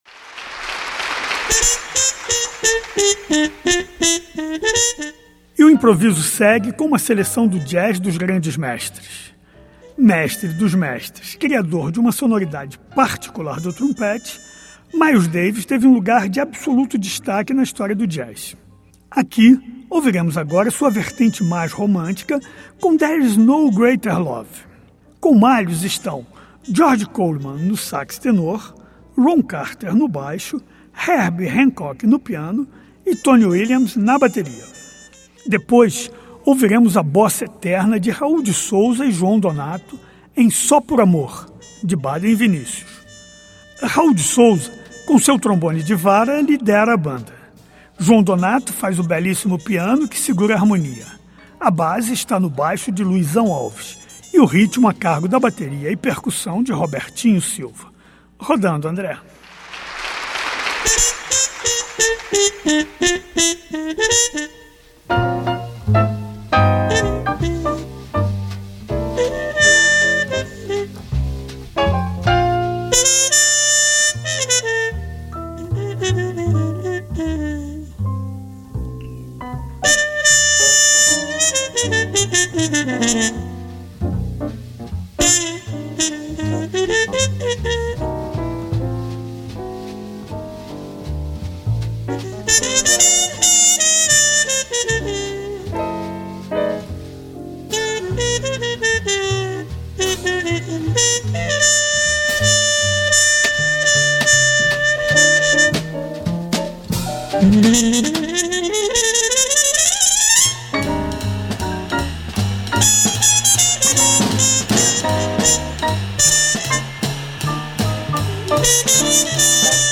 Jazz Samba